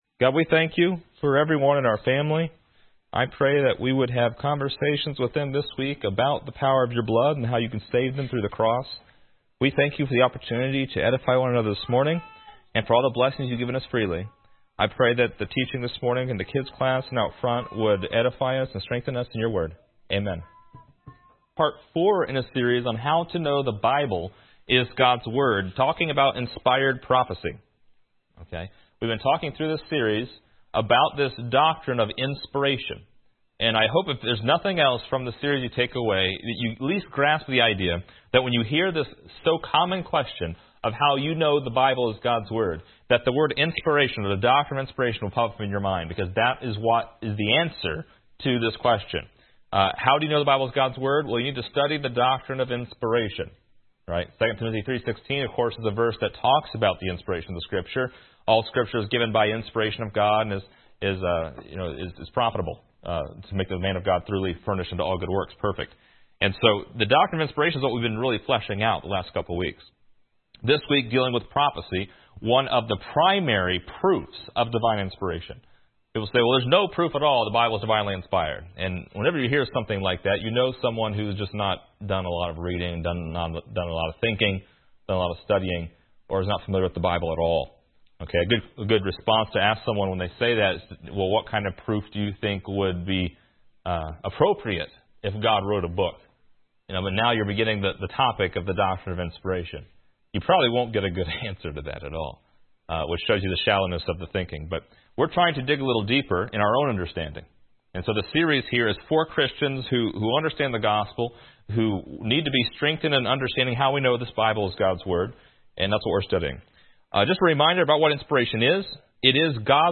Download MP3 | Download Outline Description: This is the fourth lesson in a series of lessons that covers how you can know the Bible is God’s word . The prophecies of the Bible and their fulfillment serve as a primary proof that the Bible is divinely inspired.